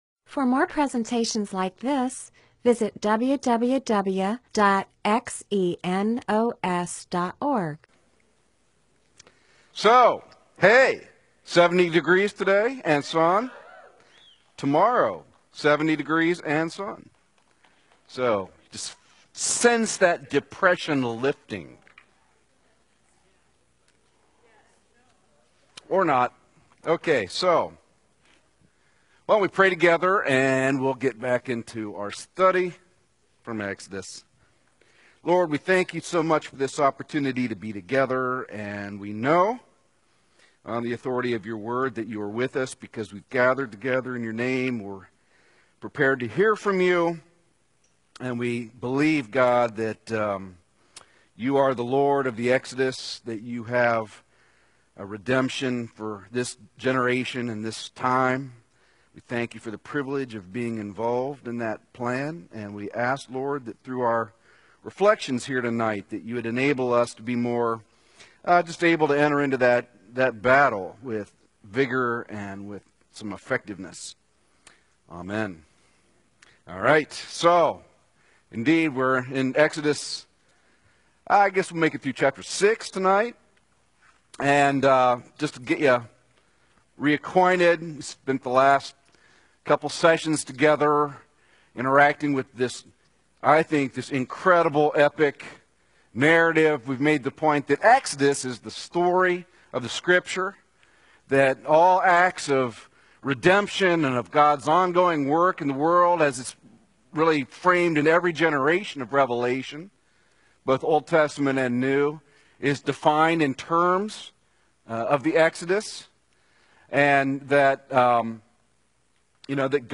Bible teaching (presentation, sermon) on Exodus 4:27-6:30